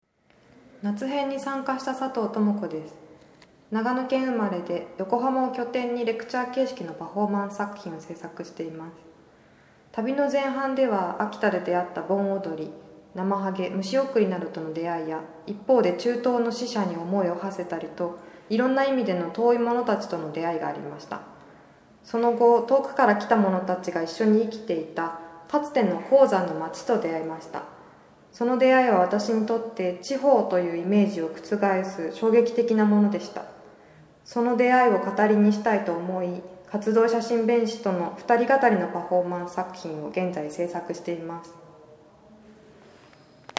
昨年度の「旅する地域考」参加者から いまも印象に残ることなどを 30 秒でコメントいただきました。